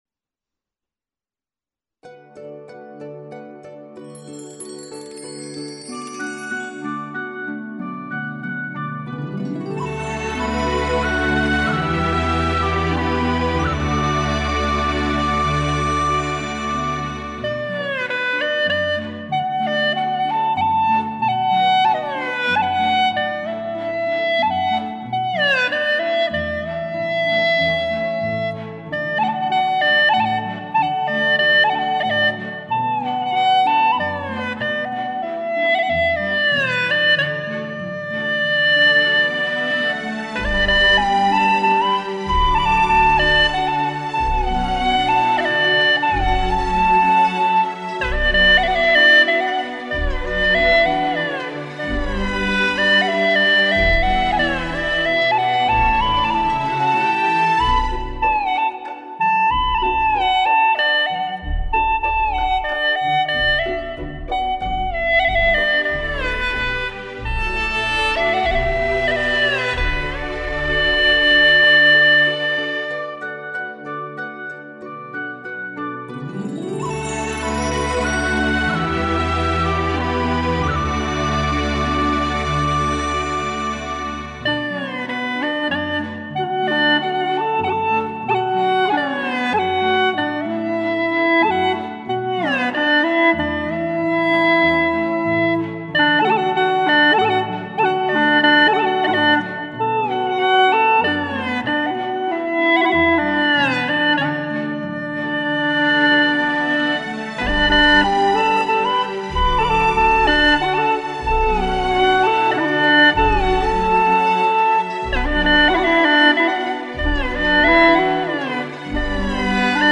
调式 : D 曲类 : 独奏
回复： 独奏曲，没有词。